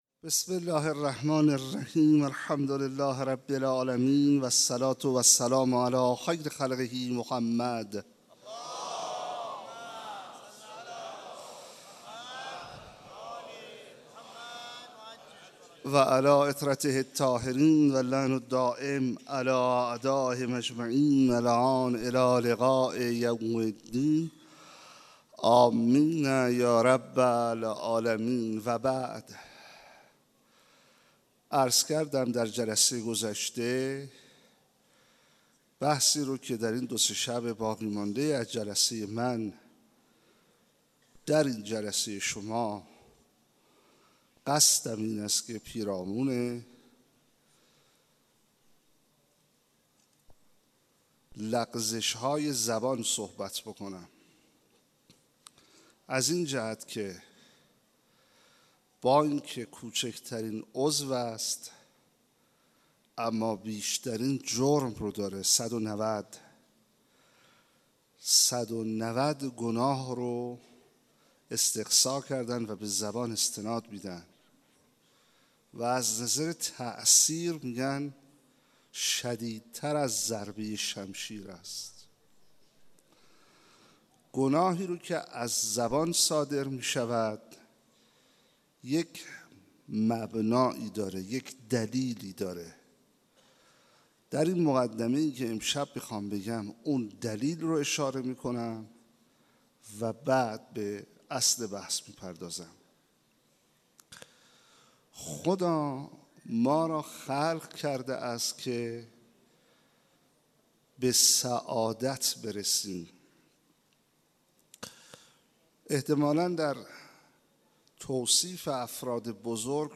شب دوم فاطميه 95 - هيئت ثارالله - سخنرانی